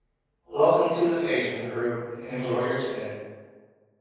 Normalized to -20 LUFS. Using pitch shifting delay effect and reverb. Also cleaned up the high frequency noise.